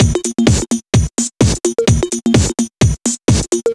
VMH1 Minimal Beats 12.wav